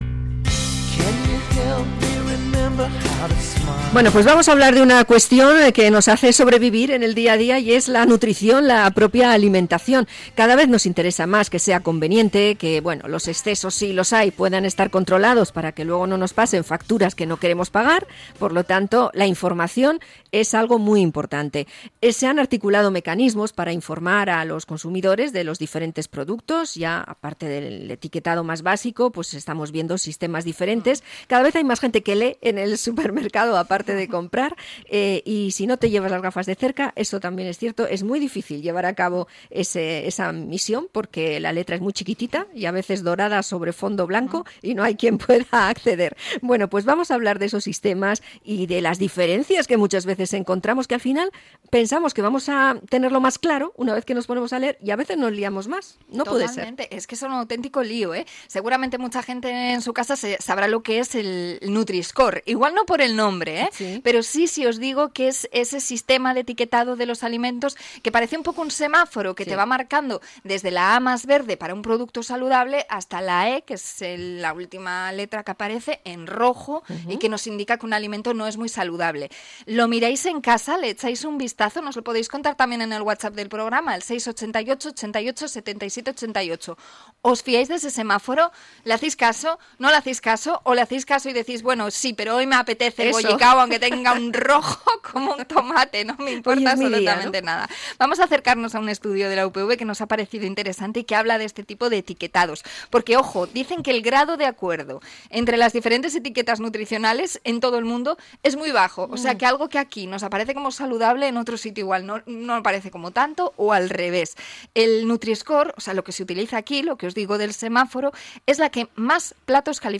Entrevista a investigadora de la UPV sobre las etiquetas nutricionales